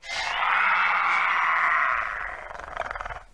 Звуки раптора
Велоцираптор приготовился к охоте